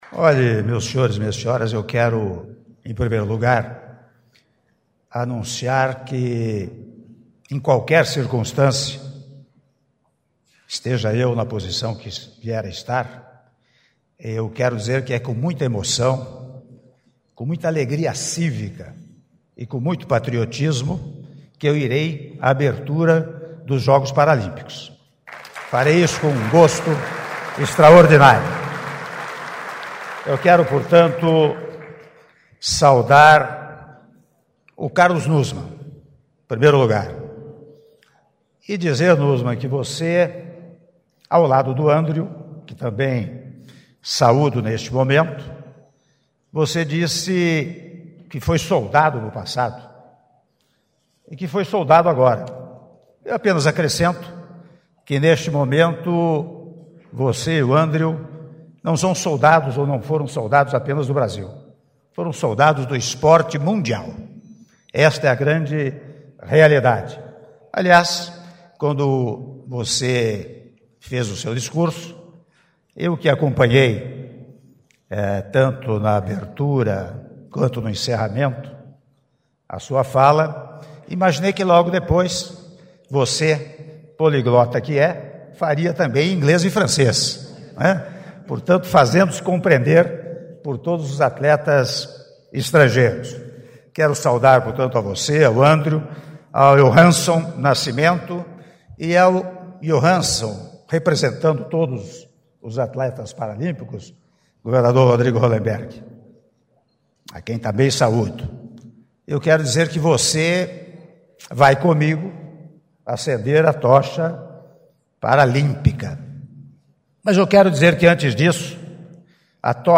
Áudio do discurso do Senhor Presidente da República em exercício, Michel Temer, durante cerimônia de Recepção da Tocha Paralímpica - Brasília/DF (07min10s)